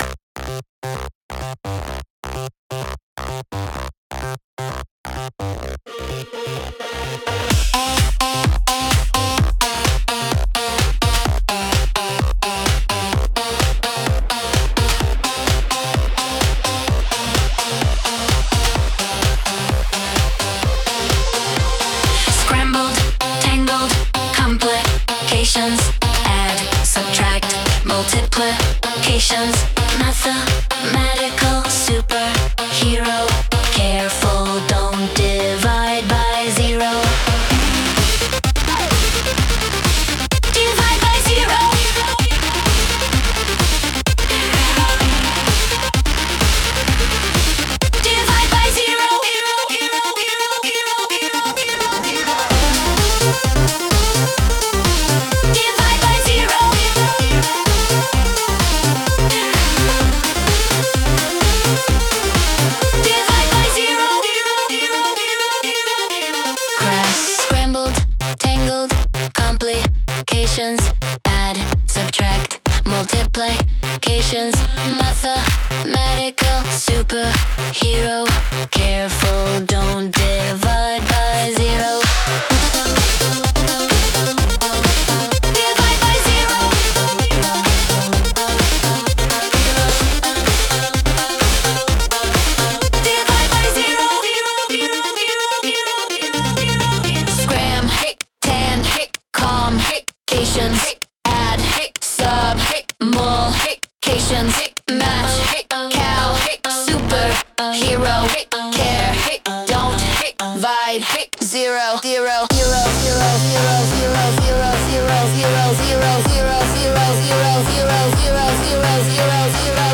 Liquid_Mushroom_(Remix)_mp3.mp3